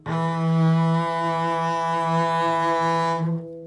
大提琴：单音 颤音 D3D4 " 大提琴 E3 颤音
描述：这是个大提琴演奏E音的乐器样本，在3个八度中。该音符的攻击和延音是Tenuto和Vibrato。所用的调谐频率（音乐会音高）为442，动态意图为中音。这个样本属于一个多样本包 乐队乐器。大提琴乐器。chordophone和弦乐器。弦乐器音符：E八度。3音乐会音高：442Hz动态：Mezzoforte攻击。攻击：Tenuto持续。颤音麦克风。ZoomH2N话筒设置。XY
标签： 多重采样 放大H2N 颤音 持续音 mezzoforte E-3 串仪器 弦鸣乐器 大提琴
声道立体声